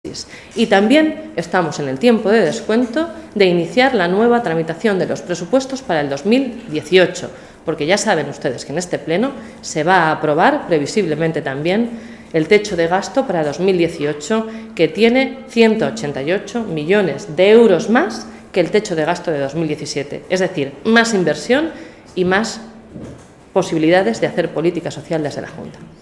La portavoz del Grupo socialista en las Cortes de Castilla-La Mancha, Blanca Fernández, ha asegurado hoy que “estamos en el tiempo de descuento para aprobar los presupuestos de 2017, los mejores presupuestos con los que va a contar nuestra región desde que se inició la crisis”.
Cortes de audio de la rueda de prensa